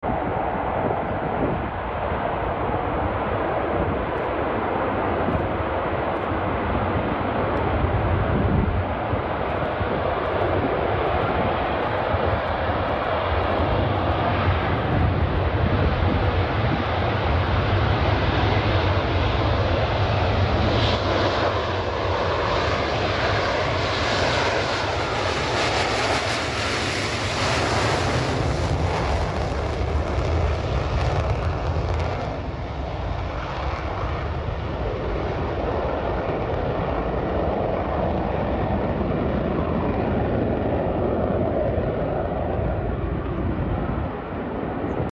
B-2 Spirit Stealth Bomber Takeoff